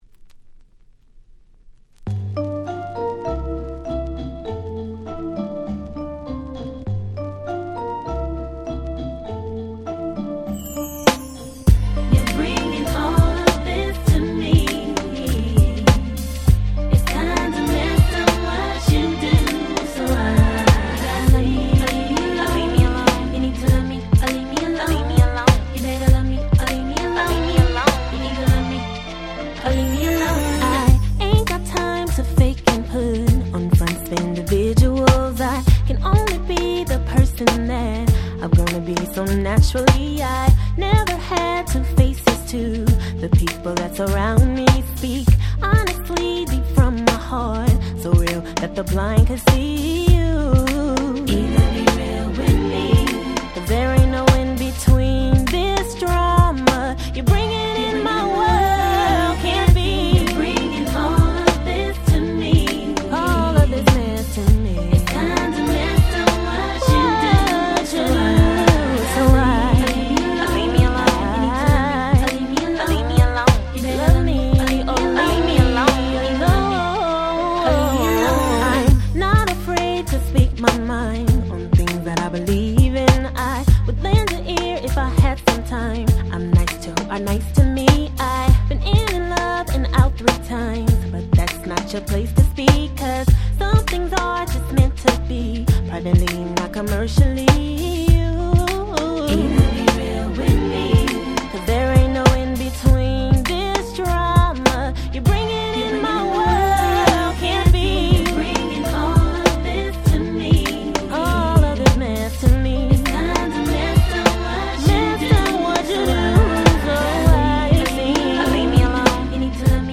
02' Very Nice R&B !!
可愛くてまったりした美メロ曲です！めちゃ良い！